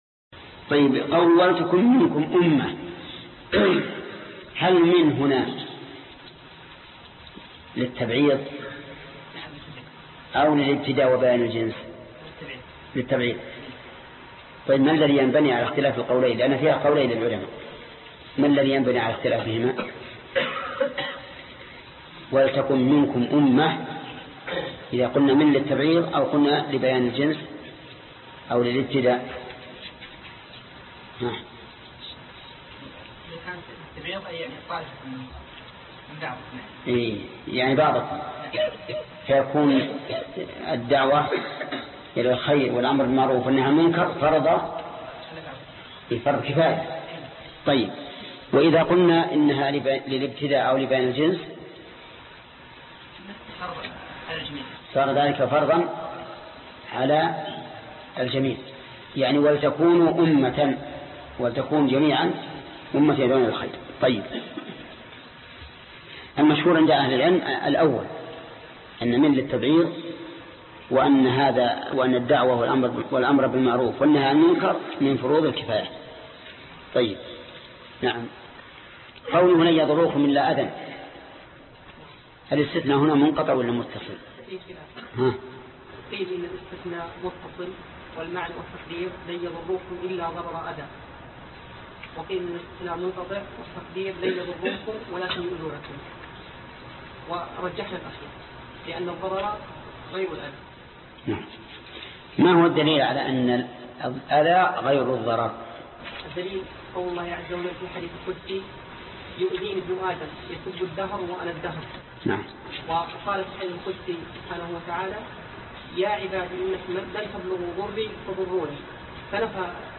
الدرس 196 تفسير الآيات 113 الى 116 (تفسير سورة آل عمران) - فضيلة الشيخ محمد بن صالح العثيمين رحمه الله